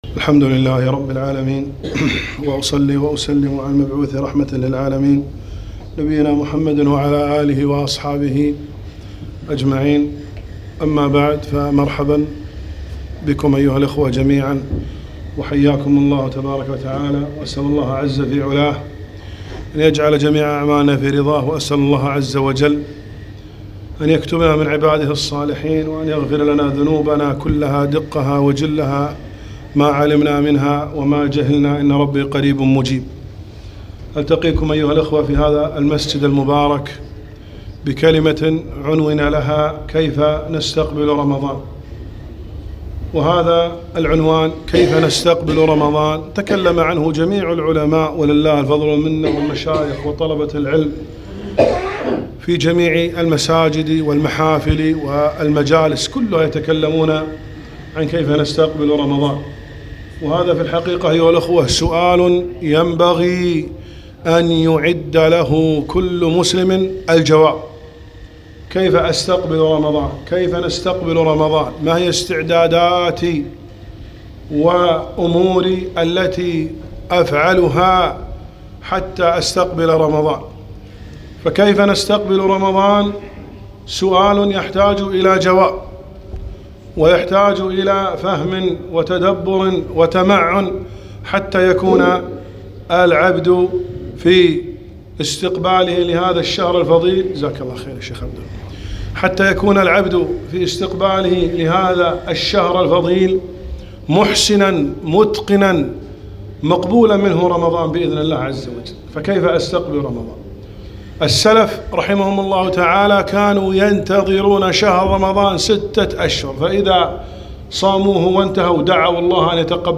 محاضرة - كيف نستقبل رمضان